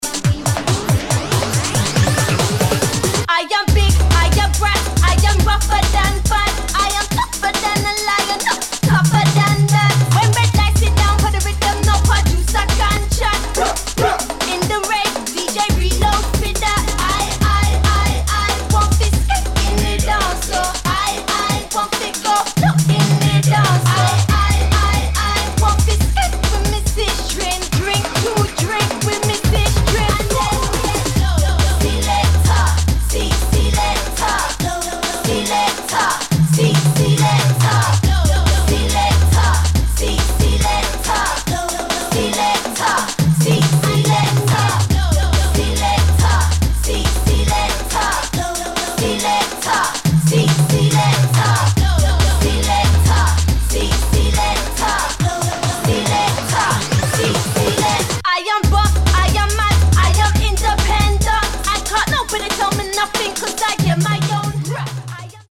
[ UK GARAGE / GRIME ]